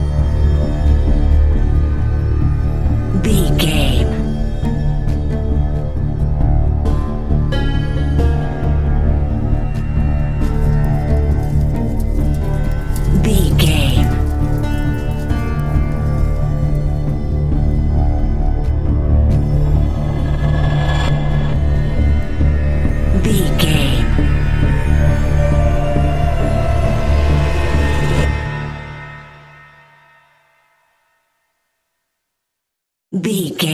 Aeolian/Minor
synthesiser
percussion
ominous
creepy